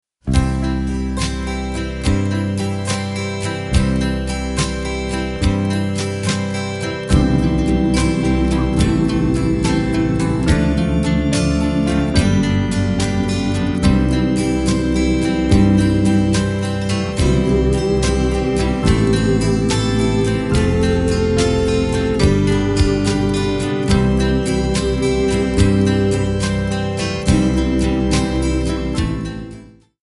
Backing track Karaoke
Pop, Oldies, 1950s